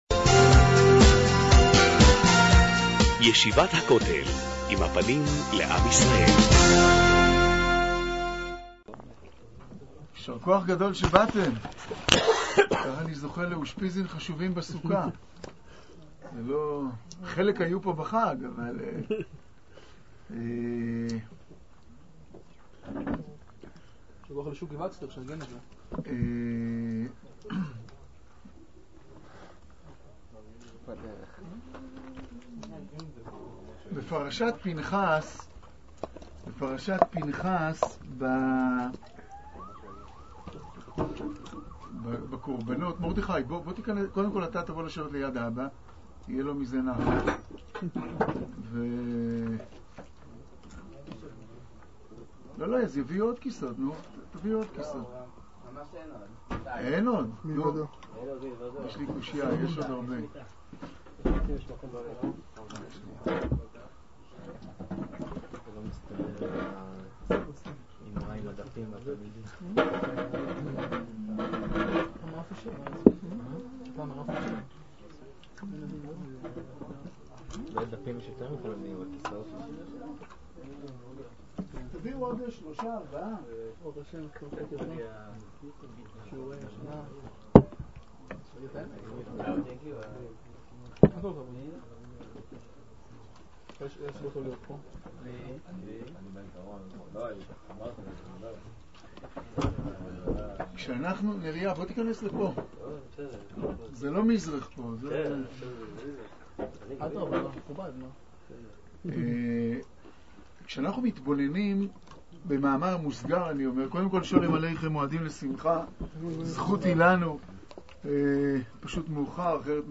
תשע"ד להאזנה לשיעור